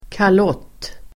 Uttal: [kal'åt:]